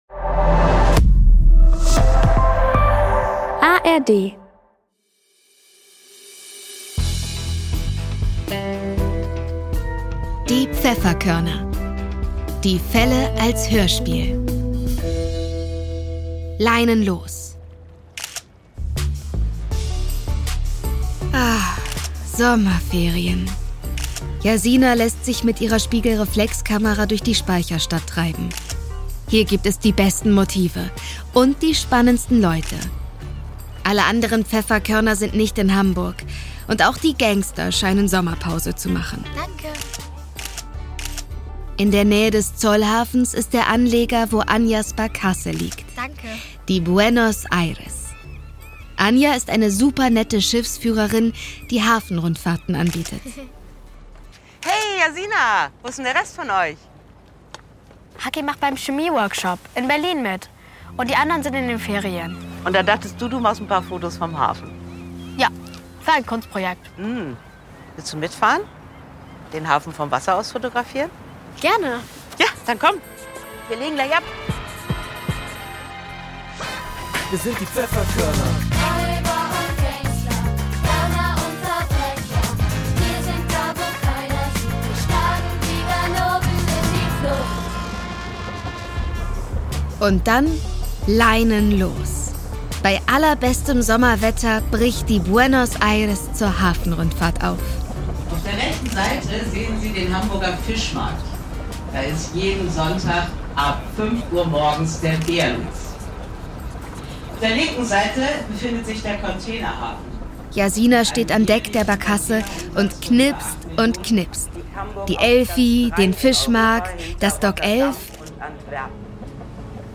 Folge 19 - Leinen los! ~ Die Pfefferkörner - Die Fälle als Hörspiel Podcast